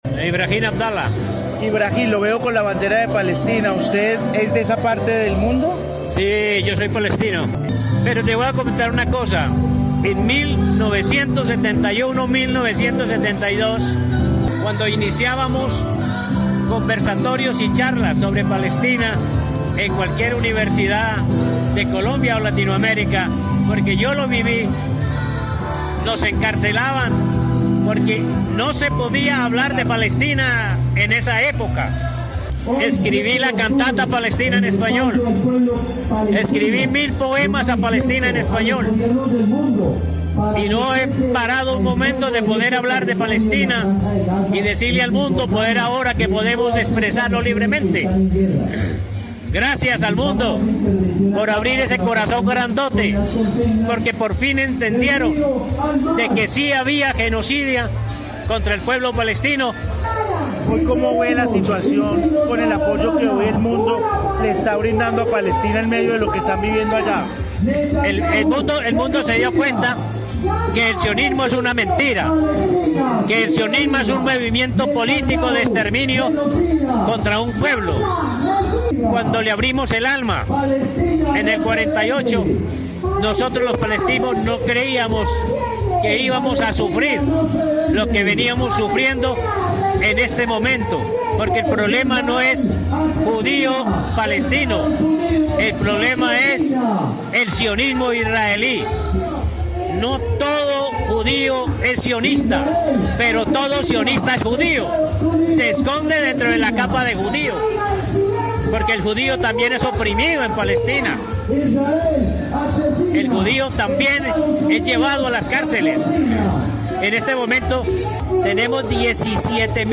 En Armenia hubo marcha, plantón y velatón pro Palestina